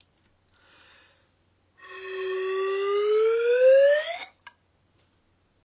uitleg uitschuifbare fluit
Als je blaast, dan hoor je een toon. Deze toon is eerst laag. Als je de buis naar beneden beweegt, dan wordt de toon hoger.
De toon is eerst laag, omdat de buis lang is. De toon wordt hoger, omdat je de buis korter maakt.